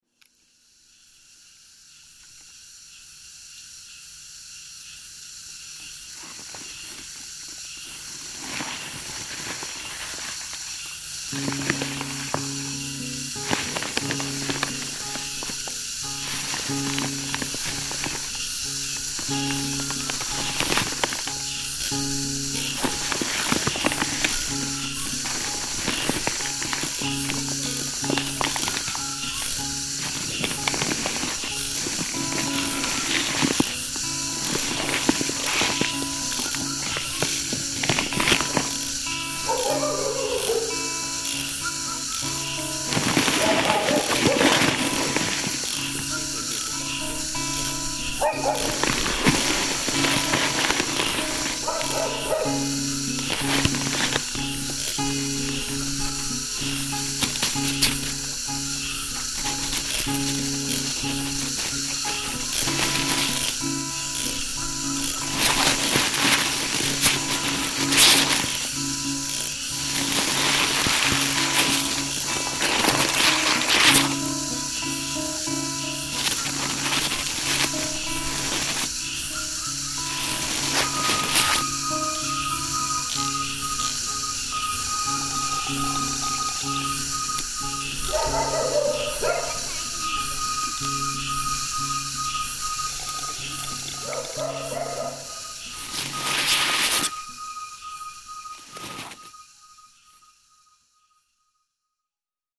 knives being sharpened
discarding paper
a simple guitar melody [expression] that weaves in, out, and under the noise. A choral note hovers toward the end.